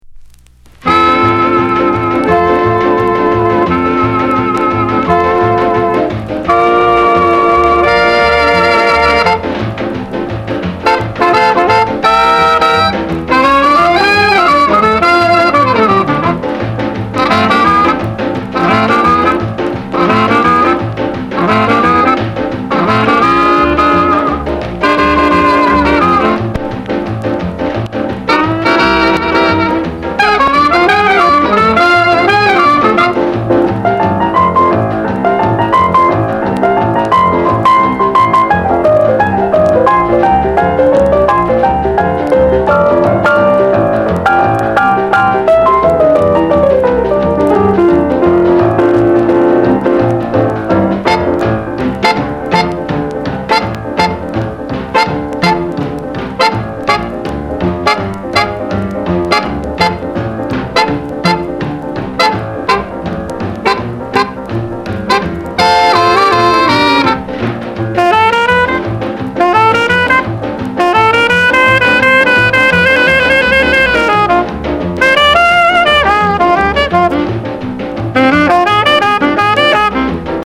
Genre: Rhythm & Blues